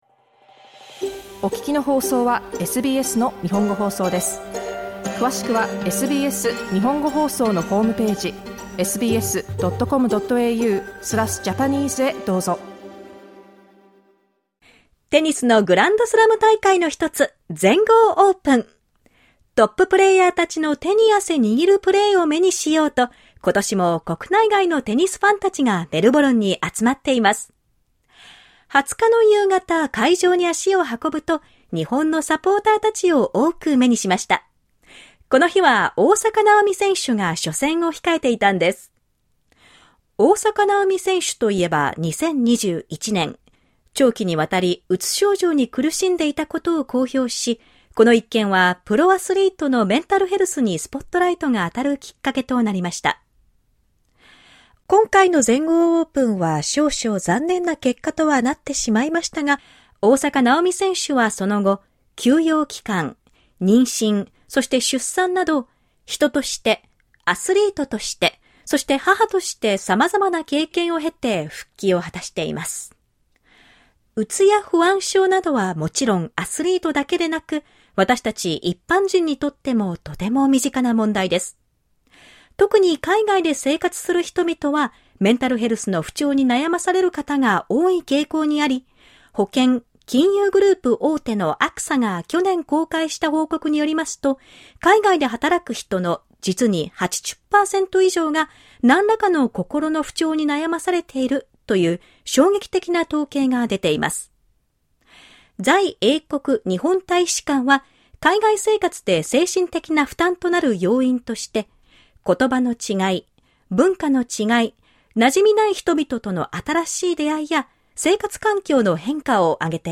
どのような症状に気を付けて、もし不調を感じた場合はどうすればよいのでしょうか？ 全豪オープンの会場で出会ったサポーターの声を交えてお届けします。